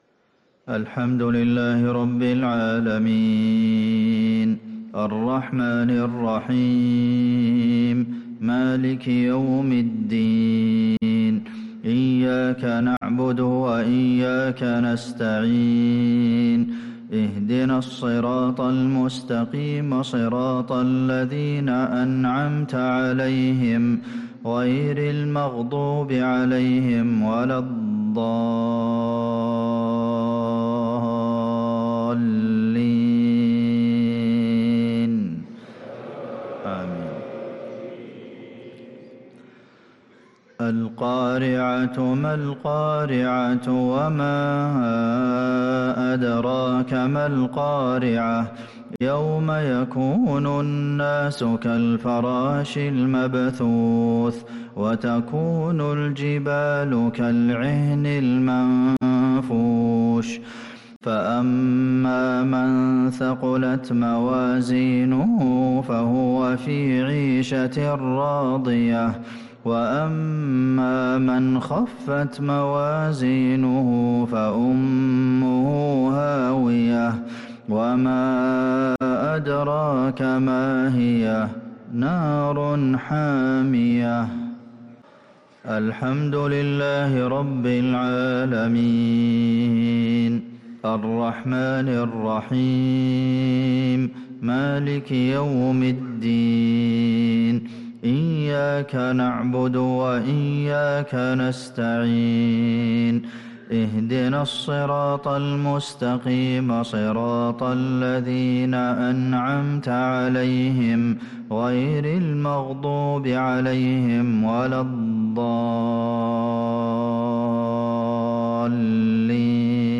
صلاة المغرب ٦-٨-١٤٤٦هـ | سورتي القارعة و الكافرون كاملة | Maghrib prayer from Surah al-Qari`ah & al-Kafirun | 5-2-2025 > 1446 🕌 > الفروض - تلاوات الحرمين